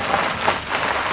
GrassSkid.wav